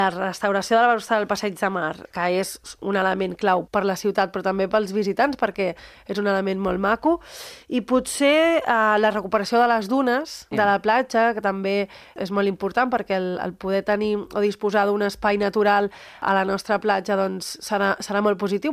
A l’entrevista política de Ràdio Calella TV, ha explicat que d’aquest objectiu se’n deriva el paquet de projectes finançats amb els 5 milions d’euros dels fons europeus NEXT GENERATION que ja s’estan començant a executar.